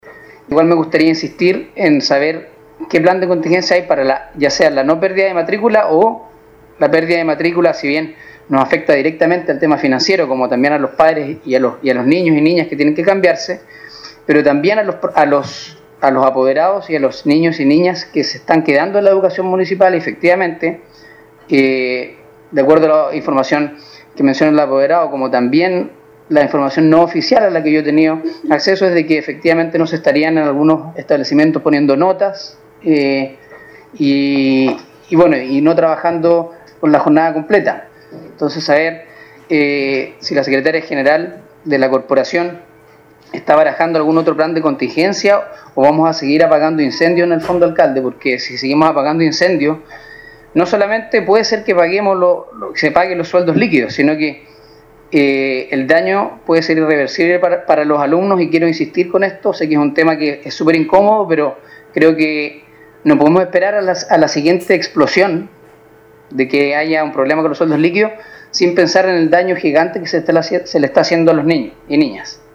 16-CONCEJAL-SAMUEL-MANDIOLA-3.mp3